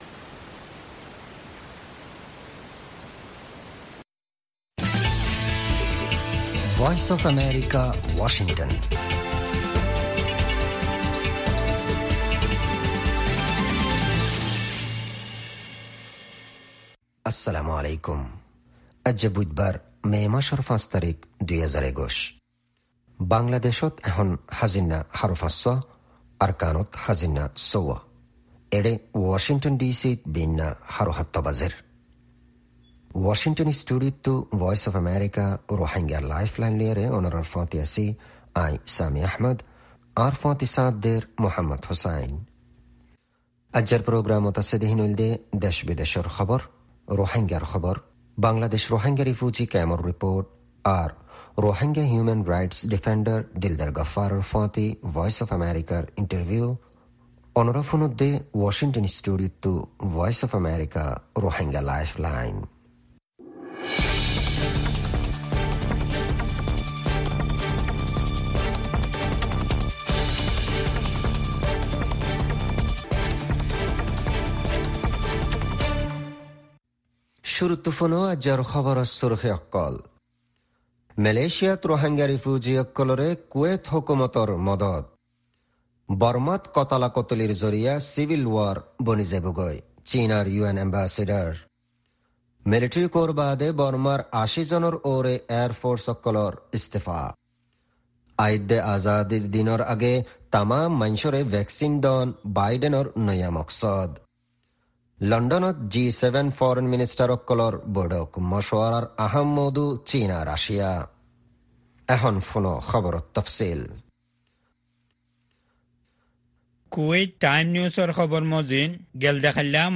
Rohingya “Lifeline” radio
News Headlines